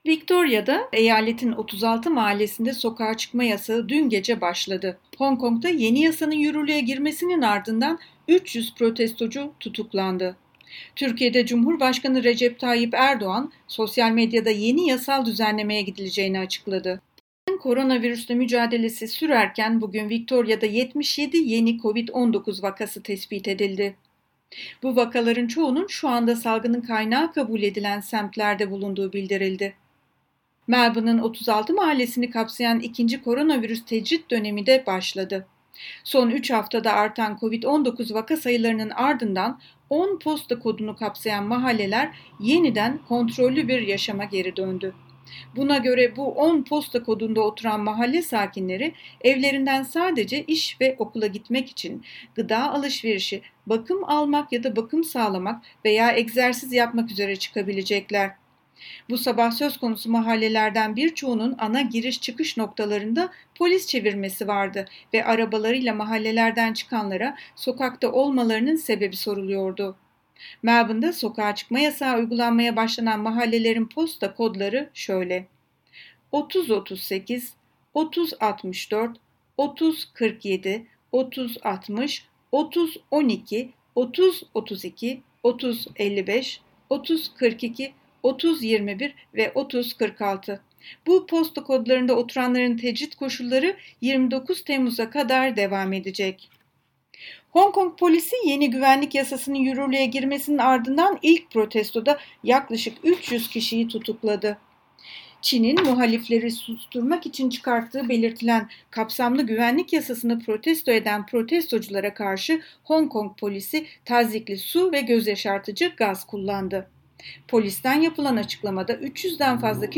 SBS Türkçe Haberler 2 Temmuz